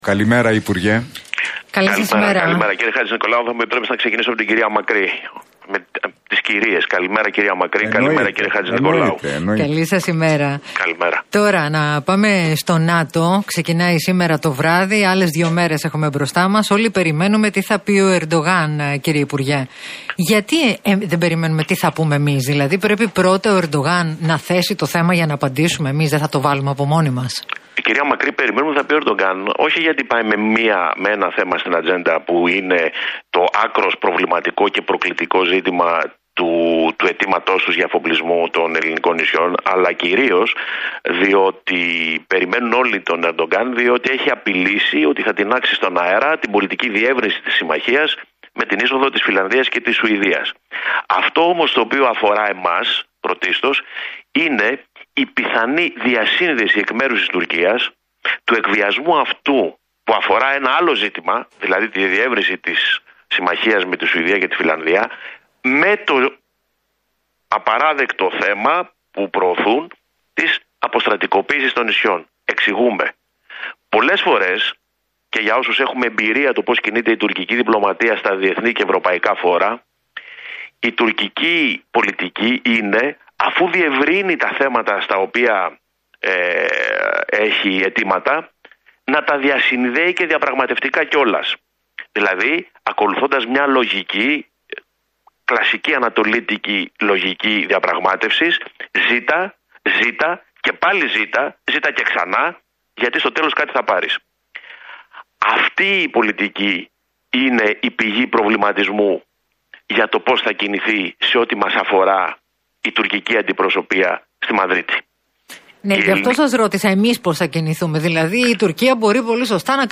Κουμουτσάκος στον Realfm 97,8: Οι δηλώσεις των Τούρκων θεμελιώνουν την ελληνική πολιτική να έχει εξοπλισμένα ως "αστακούς" τα νησιά